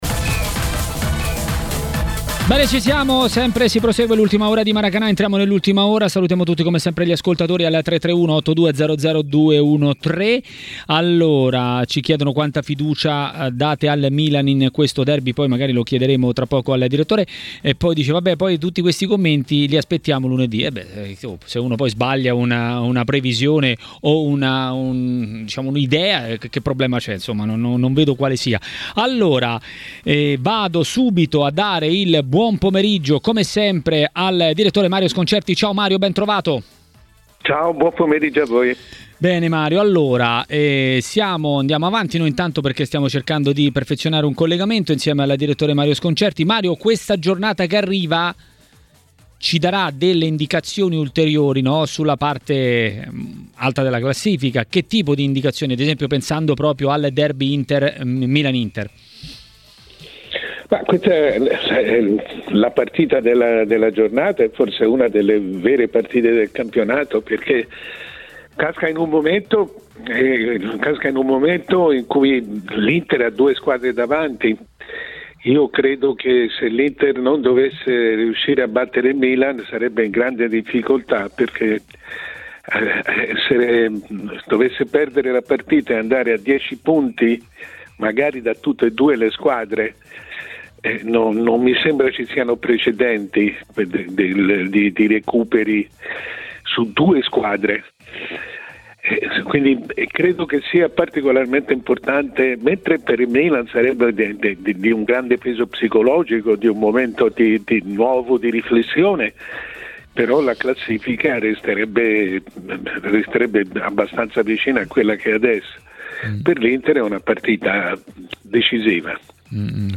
A Maracanà, nel pomeriggio di TMW Radio, è intervenuto l'ex calciatore e tecnico Fabio Cannavaro.